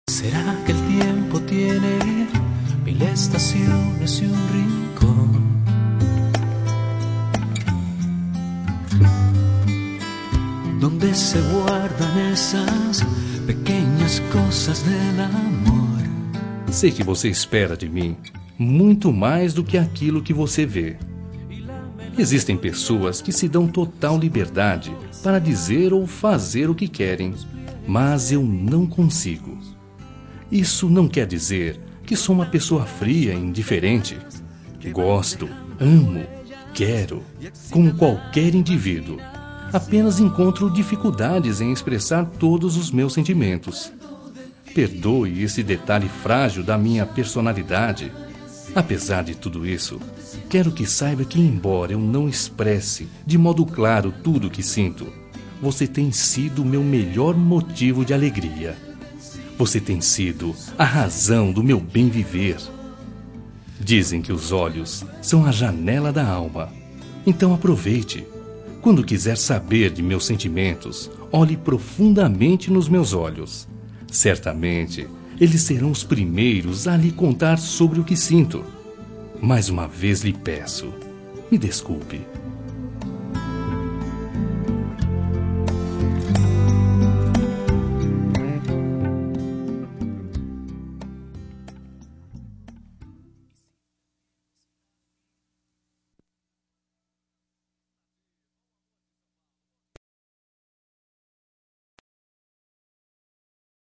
Telemensagem de Desculpas – Voz Masculina – Cód: 416